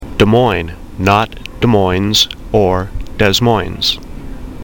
For the record, there is an official way to pronounce Des Moines and, one more time, here it is, circa 1996.
des_moines_pronunciation.mp3